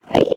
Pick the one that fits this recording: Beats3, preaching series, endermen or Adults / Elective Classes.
endermen